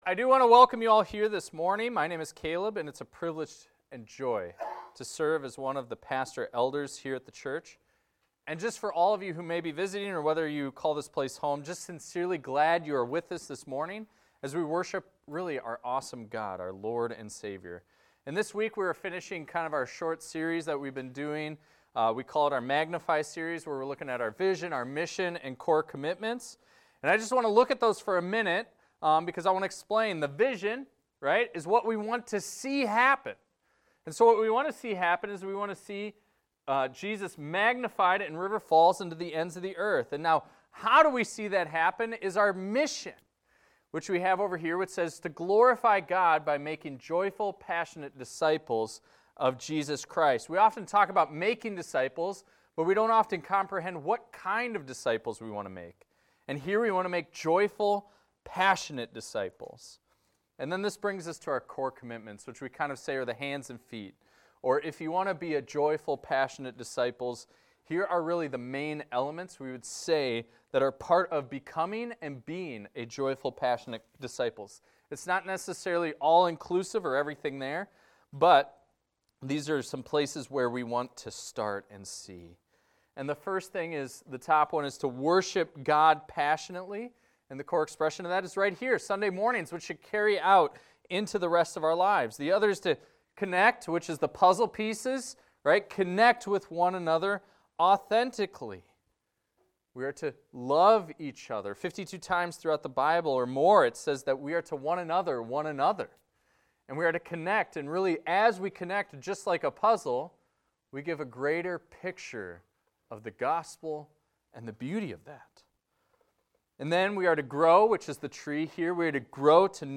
This is a recording of a sermon titled, "Go In Boldness."